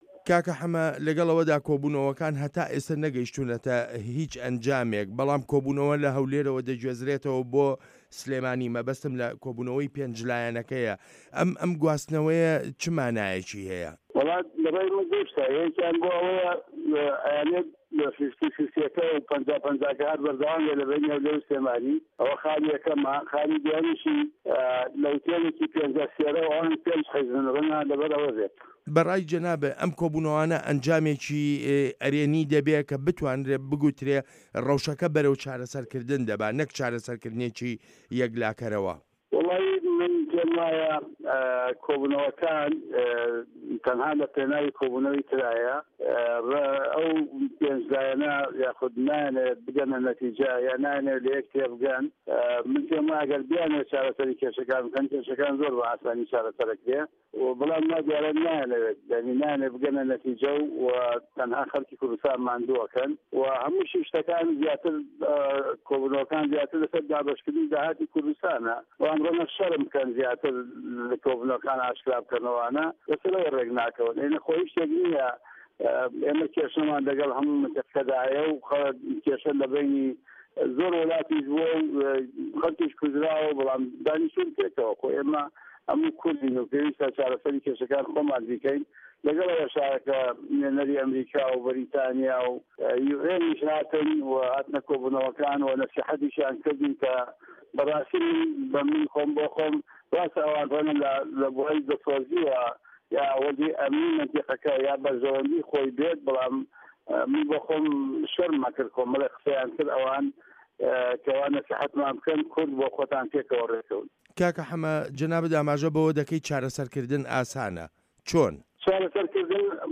وتووێژ لەگەڵ موحەمەدی حاجی مەحمود